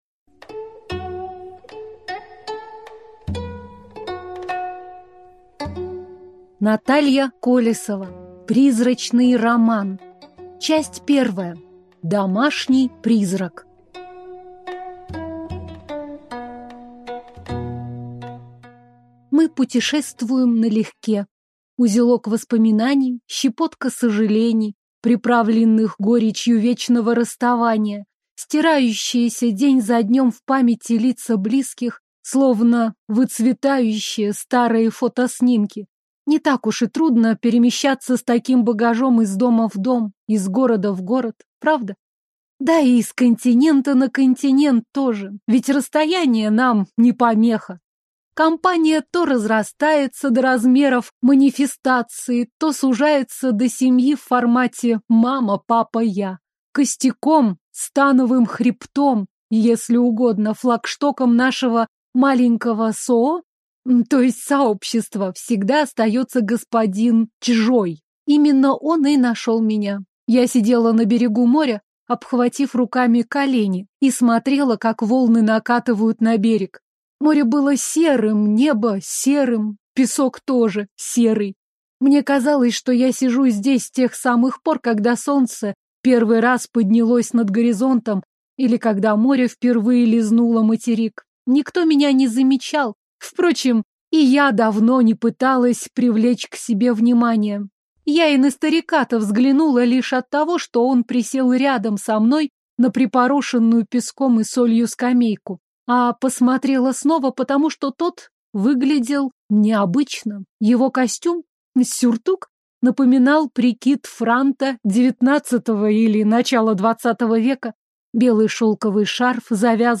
Аудиокнига Призрачный роман | Библиотека аудиокниг
Прослушать и бесплатно скачать фрагмент аудиокниги